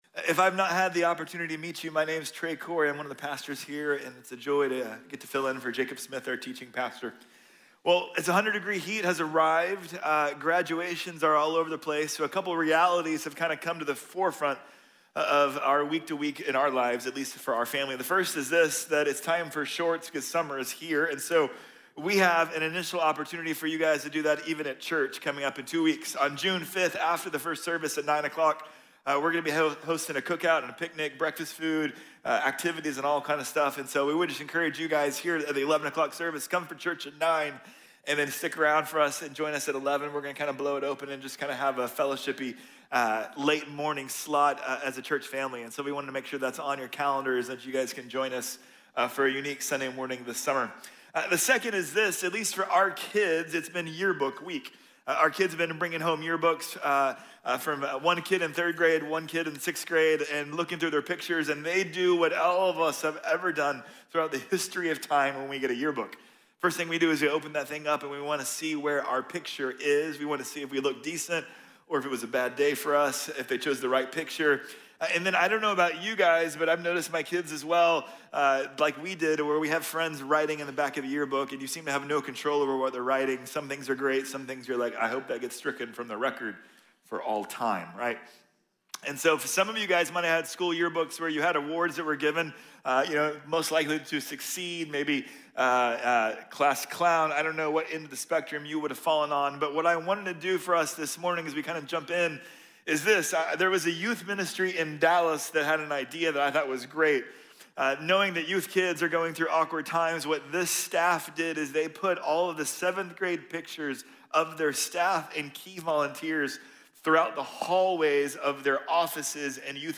Anthropology | Sermon | Grace Bible Church